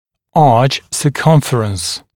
[ɑːʧ sə’kʌmfərəns][а:ч сэ’камфэрэнс]длина (сегмента окружности) дуги